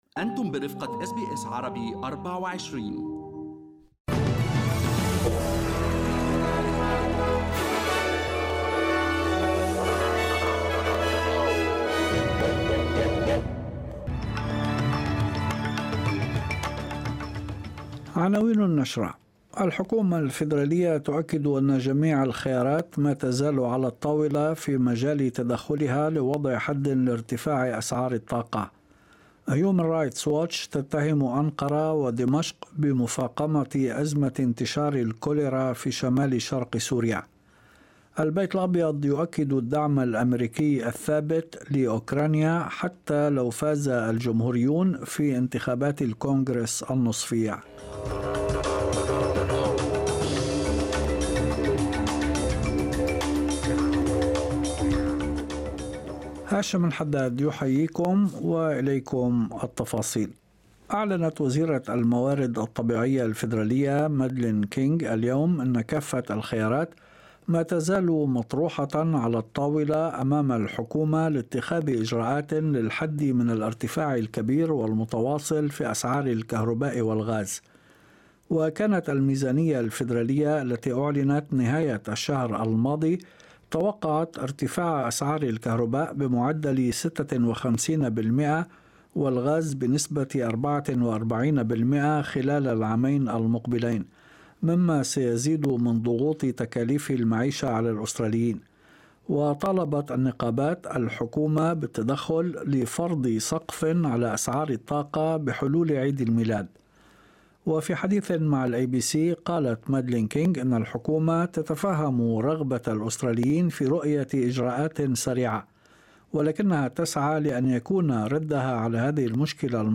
نشرة أخبار المساء 8/11/2022